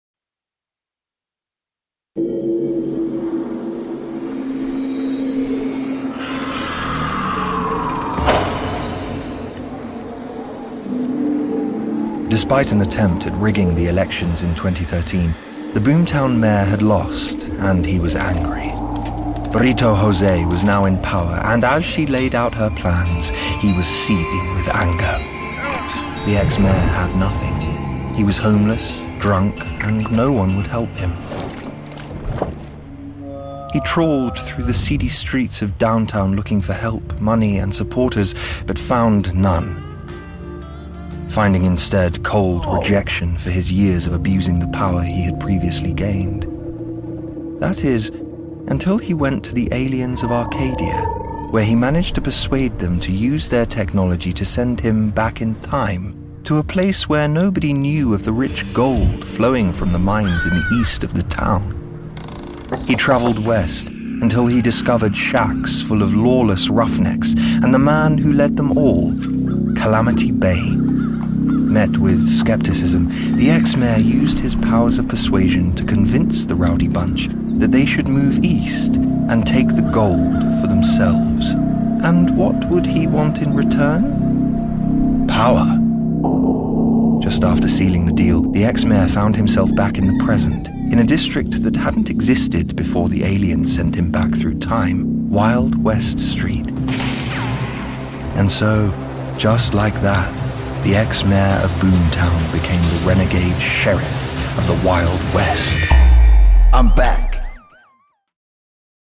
Boomtown Chapter 6 Narration